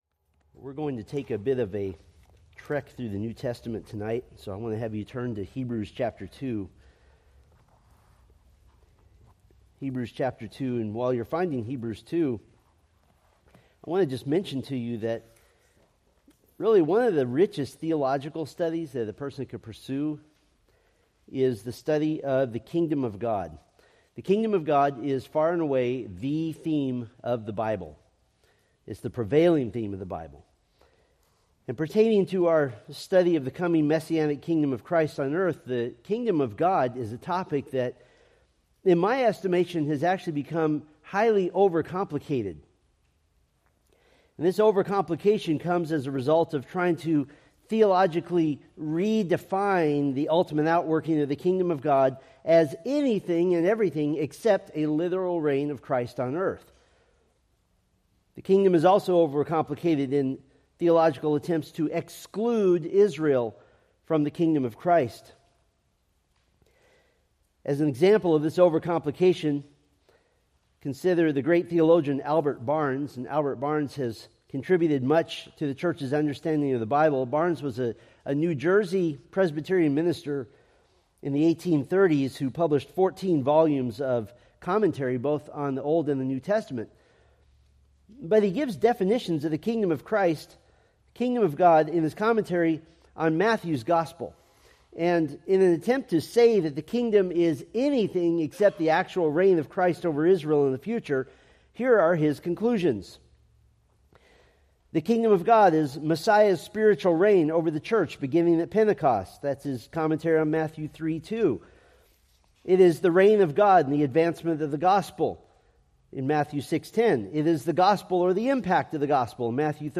Preached December 22, 2024 from Selected Scriptures